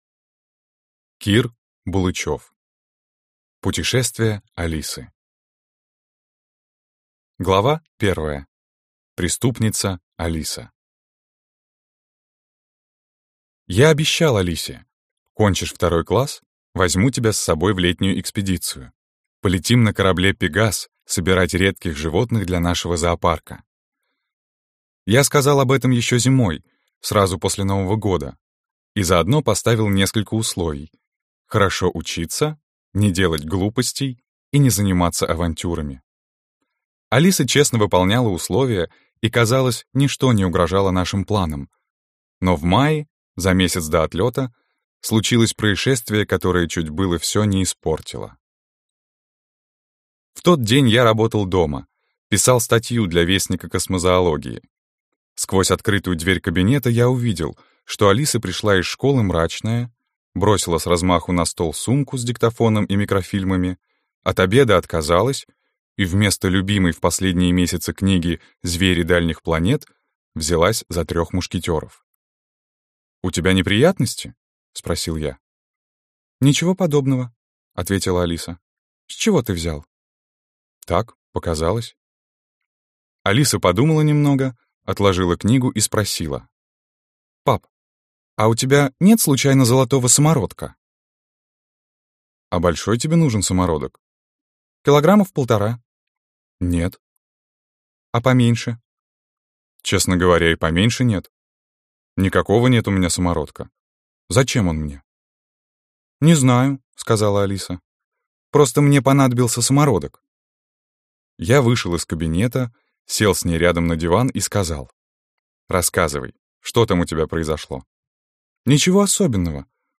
Аудиокнига Путешествие Алисы - купить, скачать и слушать онлайн | КнигоПоиск